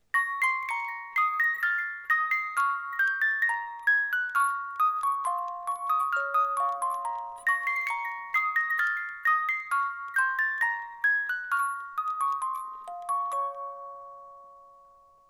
]der Korpus besteht aus Bambus
die Spieluhr besitzt ein Qualitätsspielwerk mit 18 Zungen
Der Ton dieser Spieluhren ist klar, warm und obertonreich und wird durch Kurbeln eines Qualitätsspielwerks erzeugt.
• die Spieluhr ist ein mechanisches Musikinstrument und ausdrücklich kein Spielzeug